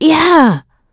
Isolet-Vox_Happy5.wav